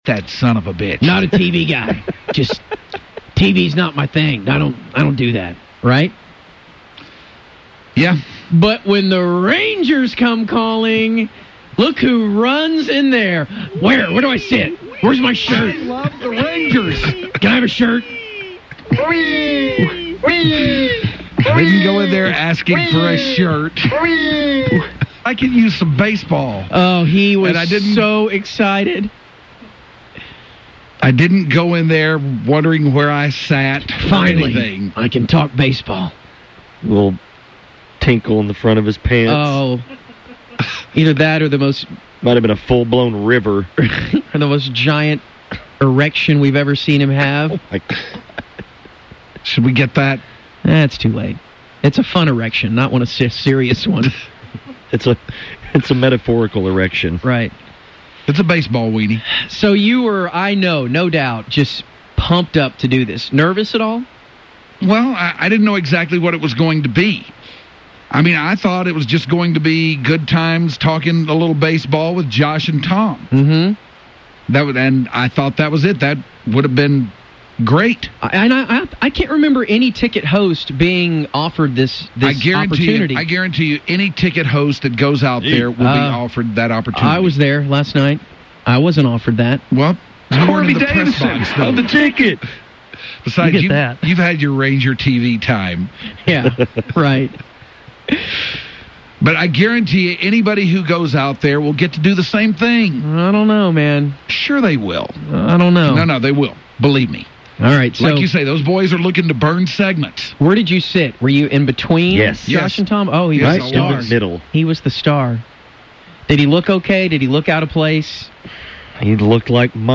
Doing play by play for his favorite team, the Texas Rangers.